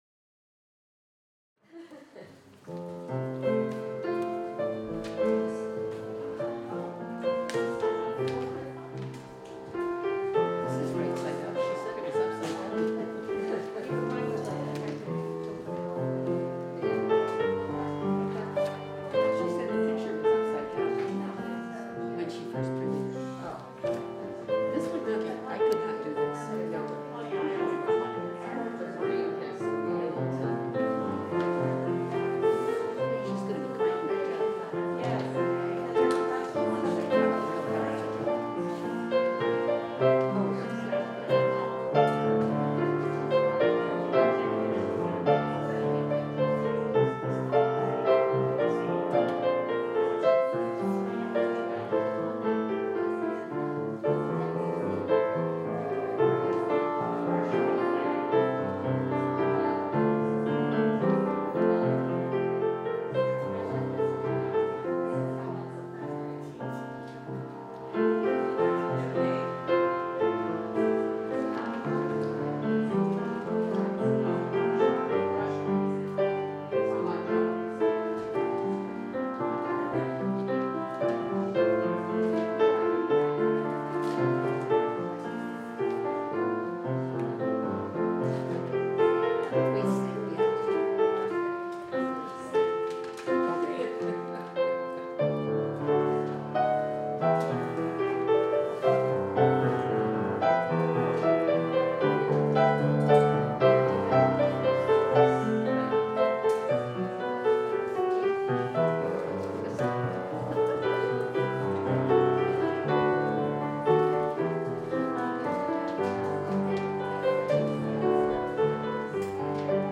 We held worship on Sunday, June 20, 2021 at 10am!